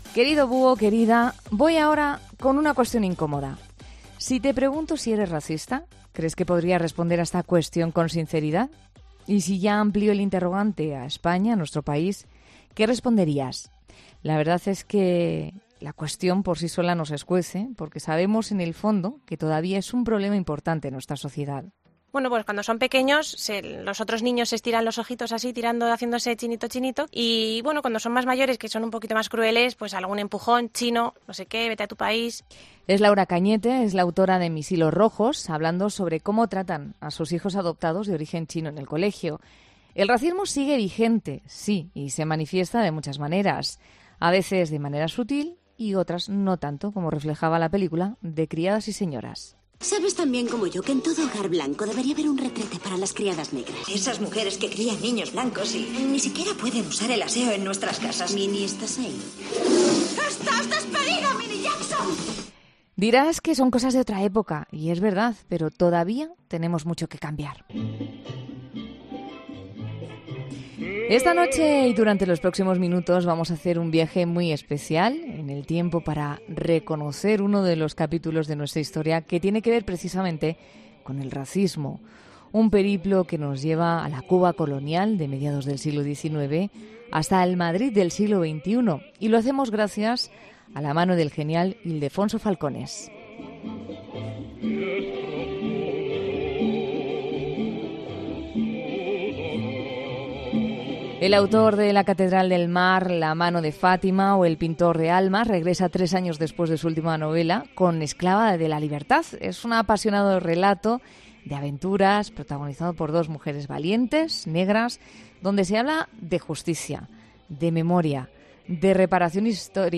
Ildelfonso Falcones en 'La Noche' de COPE